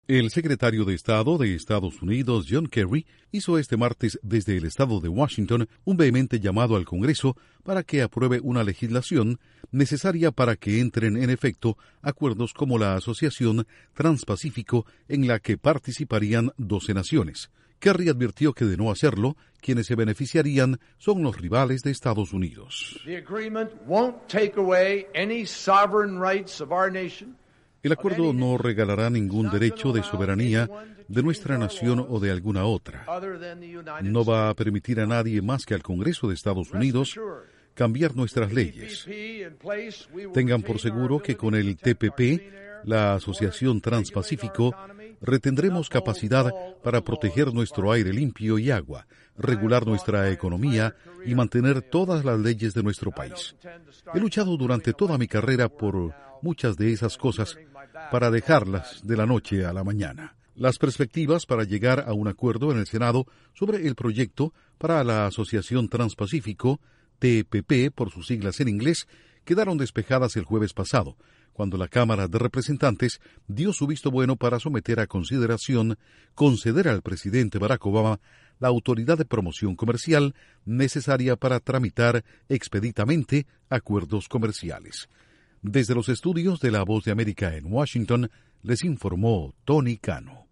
El Secretario de Estado de Estados Unidos, John Kerry, pide al Congreso aprobar una legislación comercial internacional que contemple trámites rápidos. Informa desde los estudios de la Voz de América en Washington